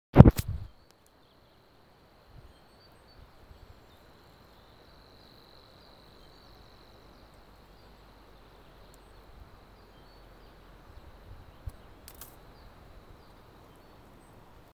Birds -> Warblers ->
Savi’s Warbler, Locustella luscinioides
StatusSinging male in breeding season